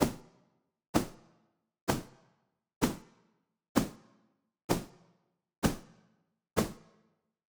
WinterTales - FootSteps.ogg